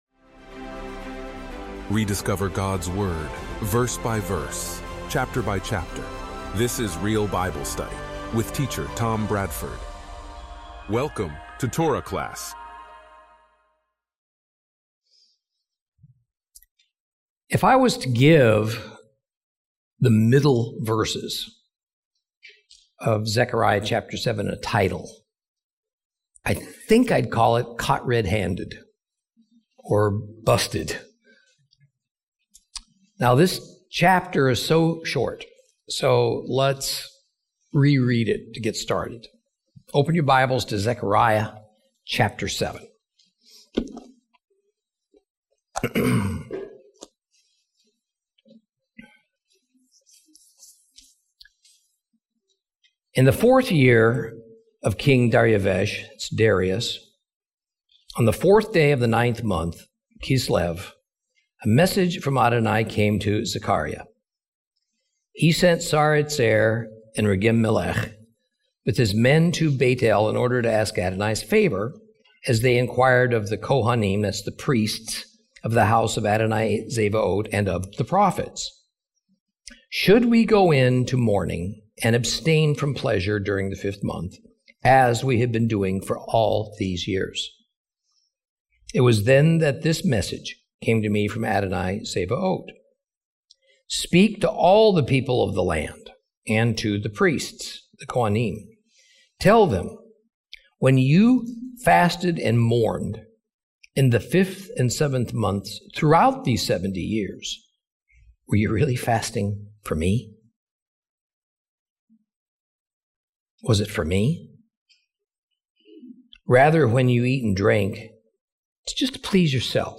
Teaching from the book of Zechariah, Lesson 13 Chapter 7.